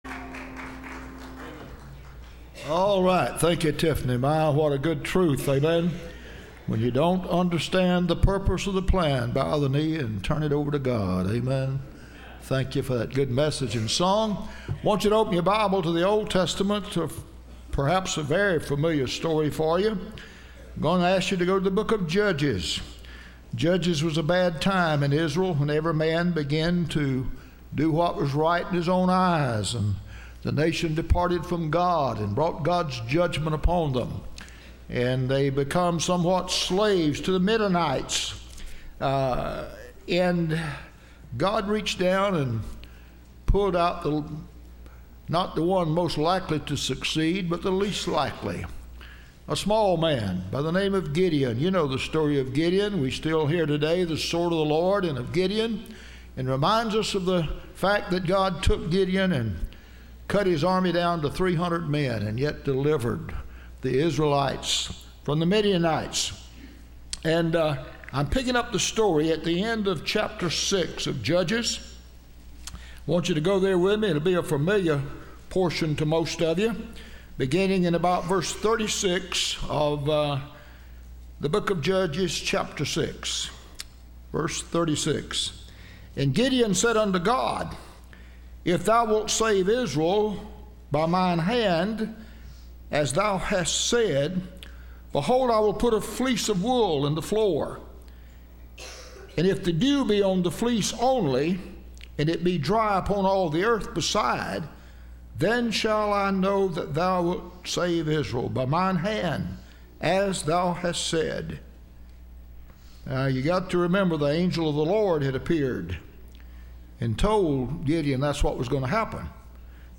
Listen to Message
Service Type: Sunday Morning